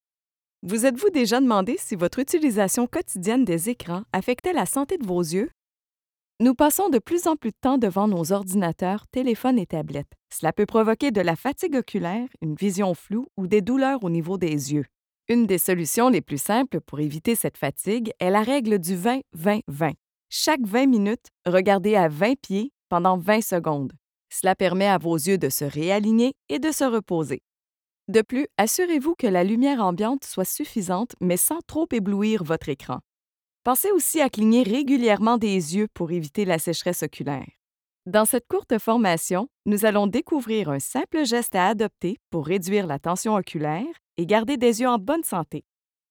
Female
French (Canadian)
Yng Adult (18-29), Adult (30-50)
E-Learning Demo
0529Formation_en_ligne.mp3